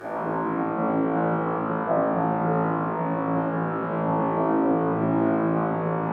drone1.wav